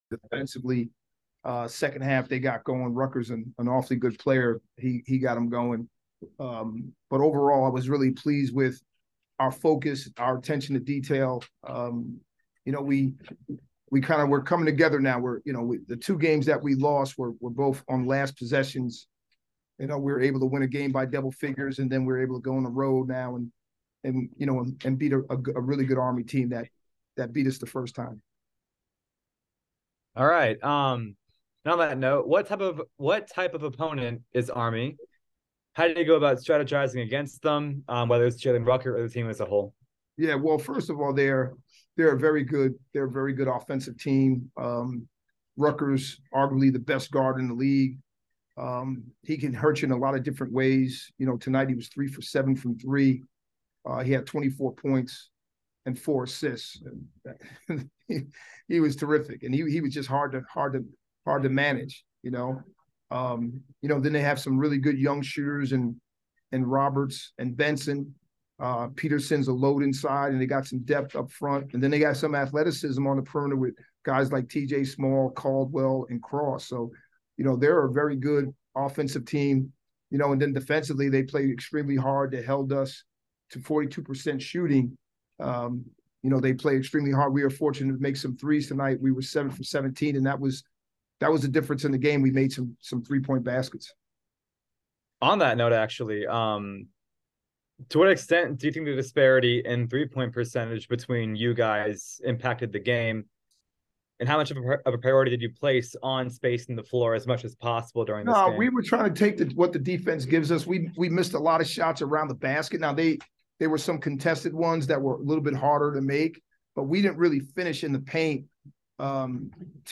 Men's Basketball / Army West Point Postgame Interview